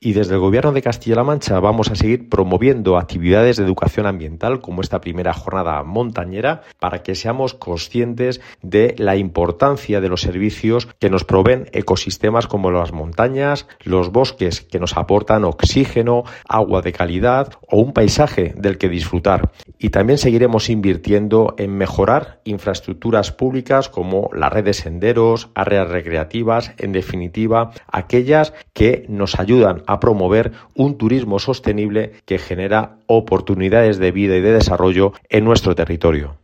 José Luis Escudero I Jornada Montañera Sierra Norte 2